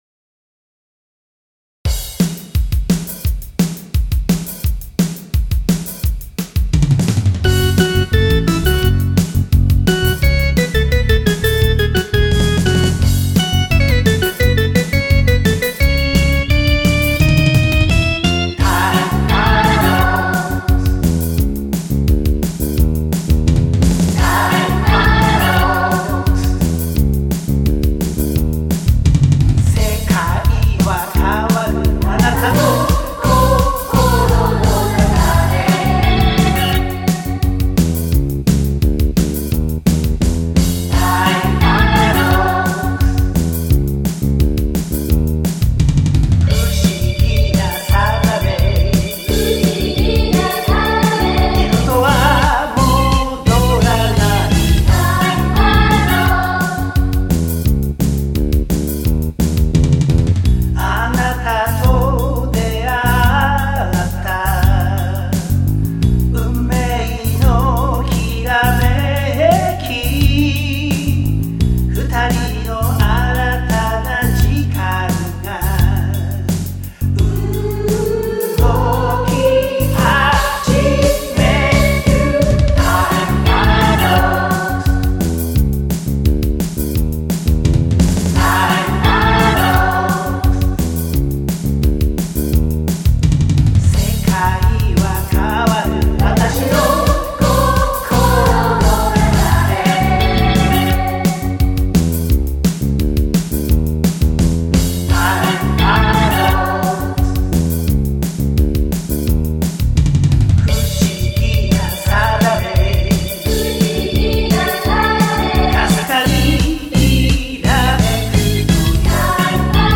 コーラス